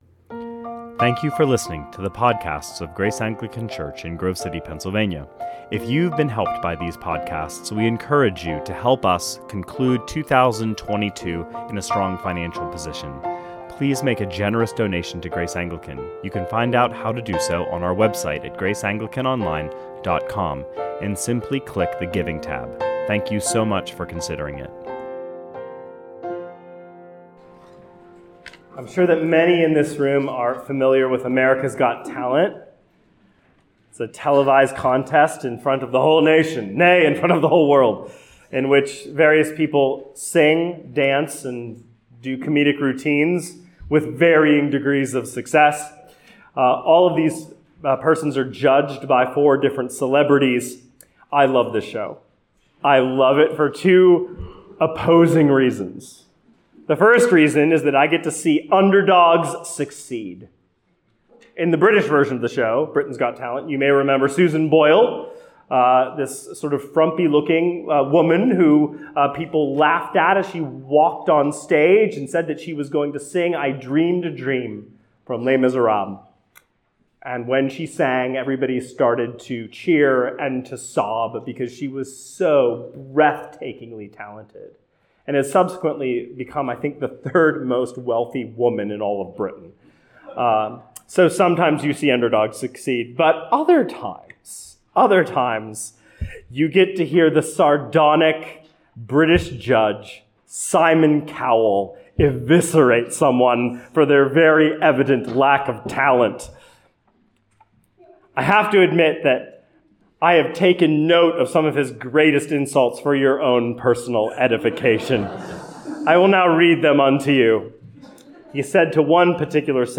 2022 Sermons